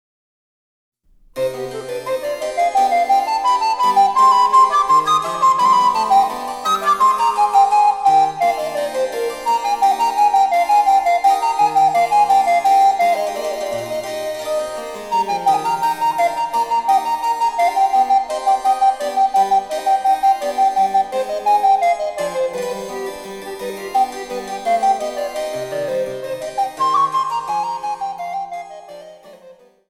第１楽章　（Ｃ−１）